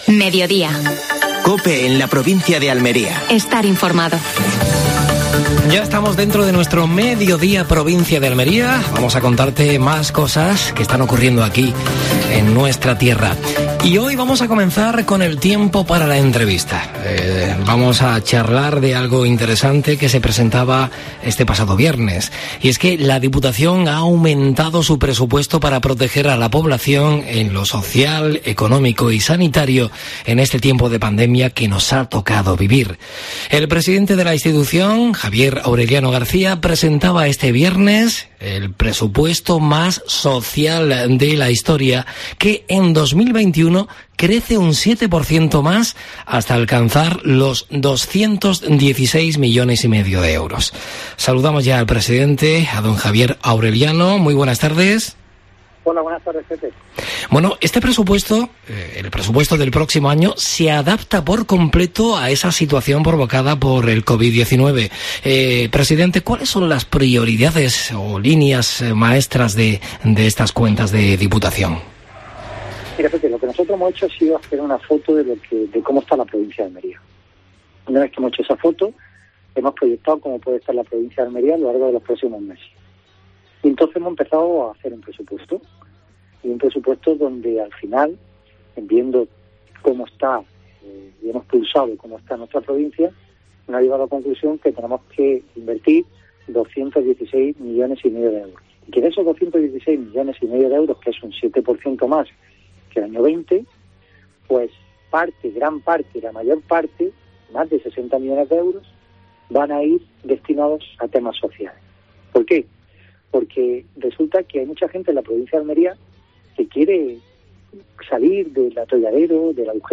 AUDIO: Actualidad en Almería. Entrevista a Javier Aureliano García (presidente de la Diputación Provincial de Almería).